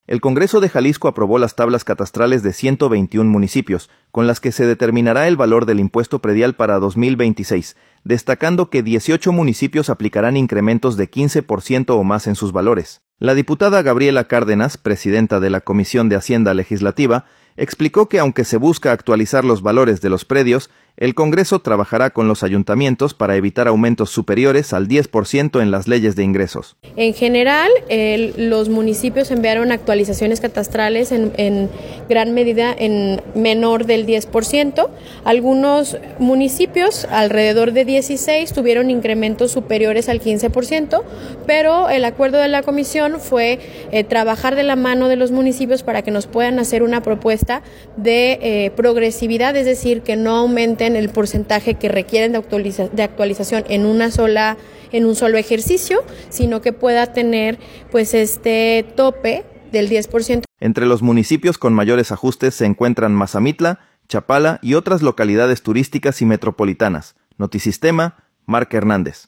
El Congreso de Jalisco aprobó las tablas catastrales de 121 municipios, con las que se determinará el valor del impuesto predial para 2026, destacando que 18 municipios aplicarán incrementos de 15 por ciento o más en sus valores. La diputada Gabriela Cárdenas, presidenta de la Comisión de Hacienda Legislativa, explicó que, aunque se busca actualizar los valores de los predios, el Congreso trabajará con los ayuntamientos para evitar aumentos superiores al 10 por ciento en las leyes de ingresos.